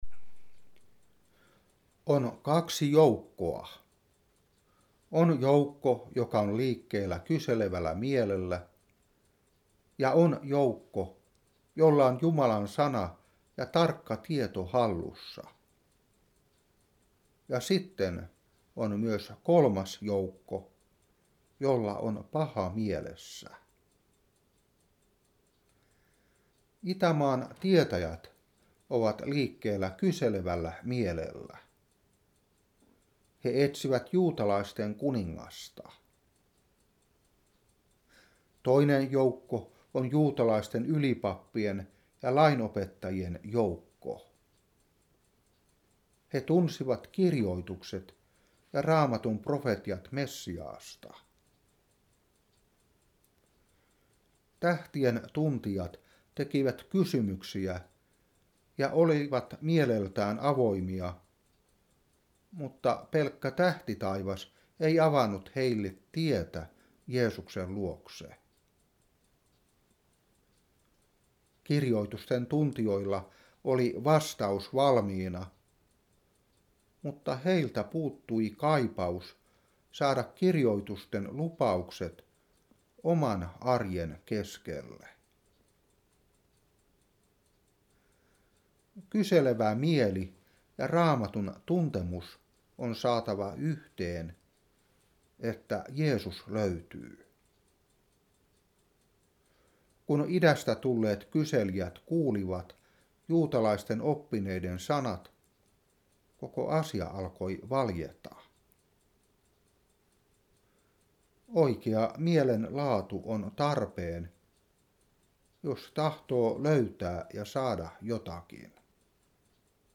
Saarna 2013-1.